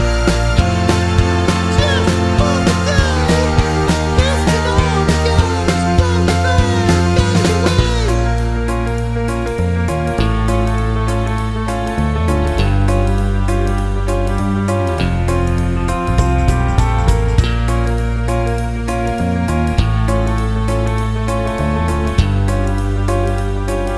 No Intro Voiceover Pop (2010s) 3:17 Buy £1.50